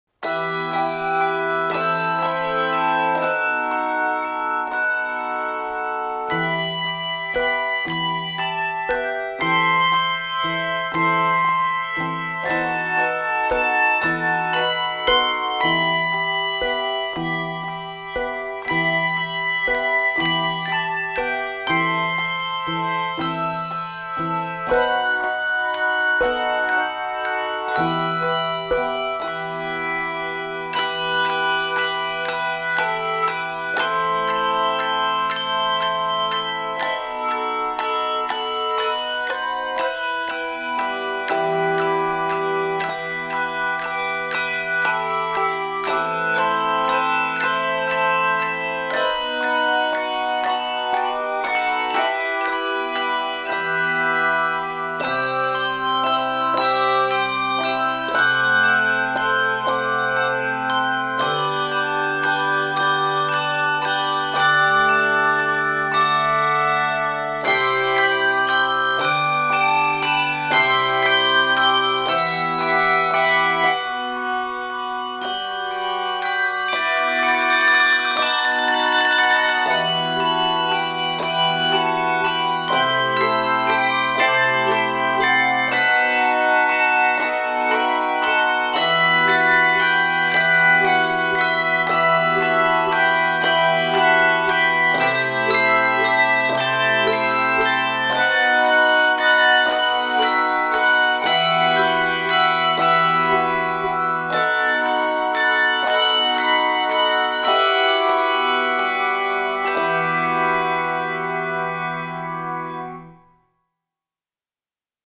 Unlike most original handbell compositions